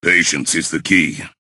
bo_lead_vo_01.ogg